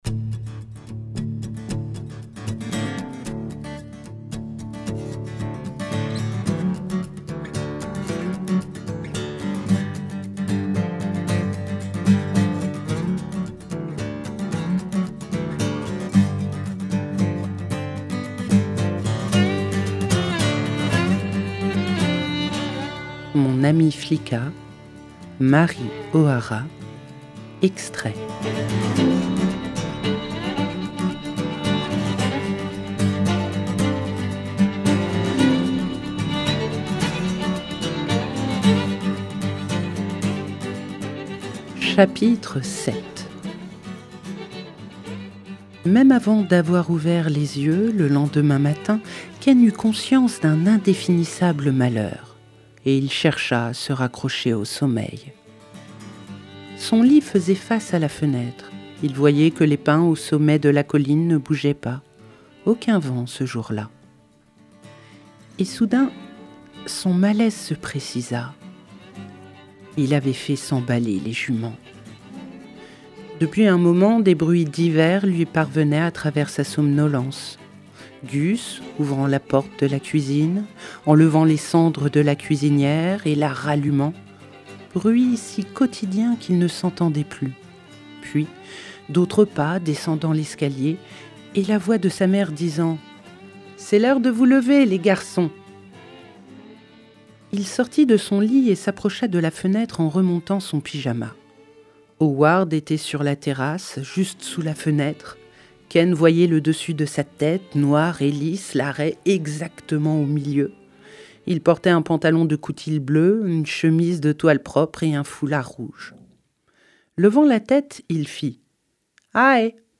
🎧 Mon amie Flicka – Mary O’Hara - Radiobook